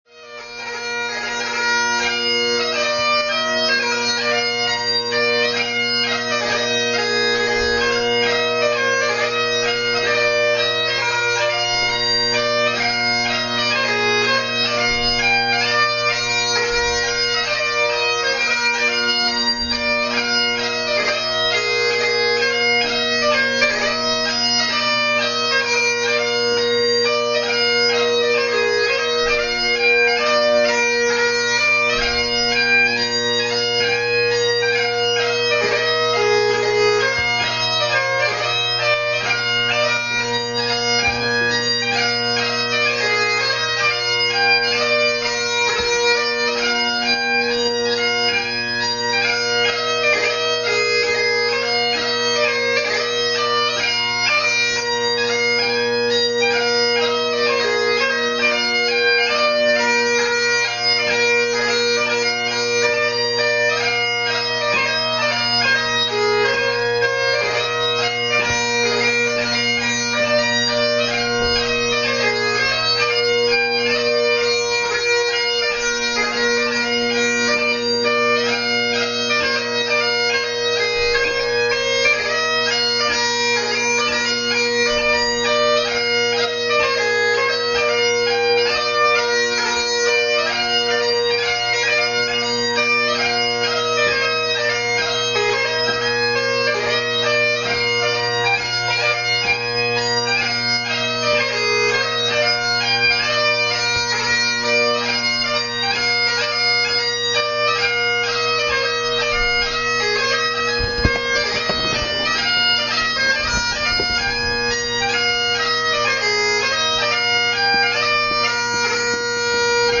Extracts from 2005 competition